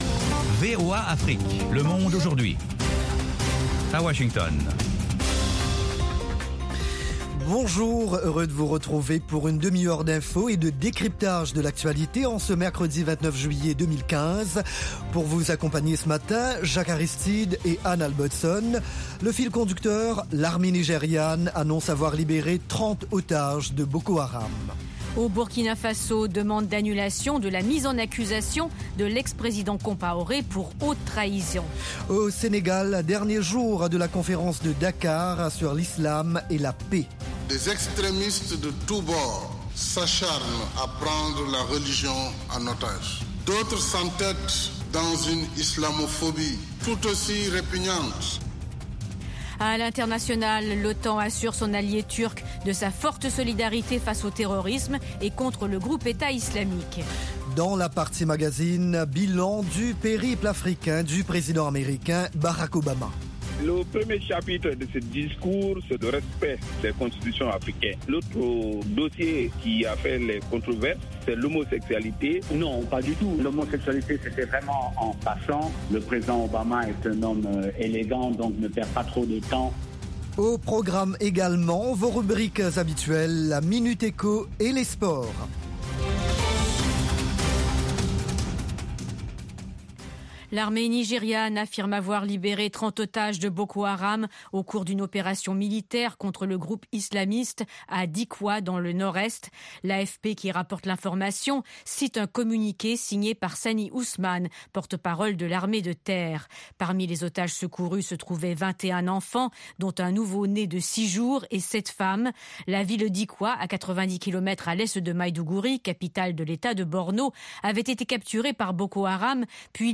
Interviews, reportages de nos envoyés spéciaux et de nos correspondants, dossiers, débats avec les principaux acteurs de la vie politique et de la société civile. Le Monde Aujourd'hui vous offre du lundi au vendredi une synthèse des principaux développementsdans la région.